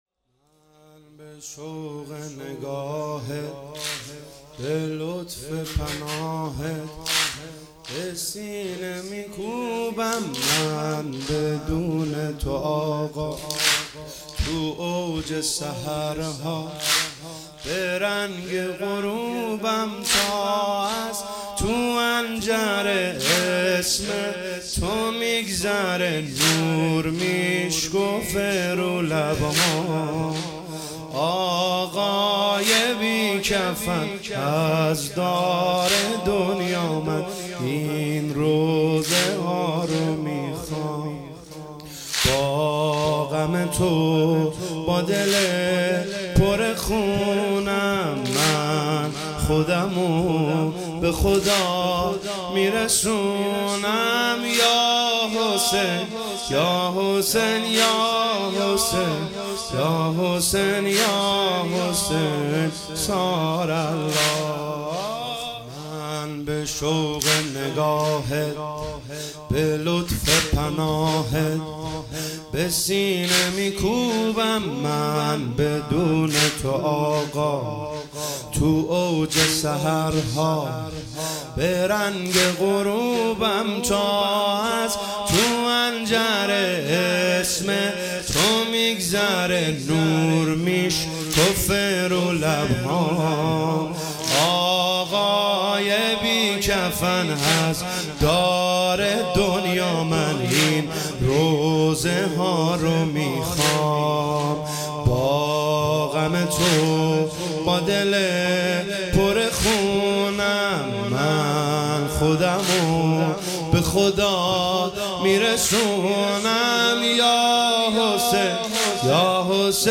شهادت حضرت رقیه(س) |هیئت ام ابیها قم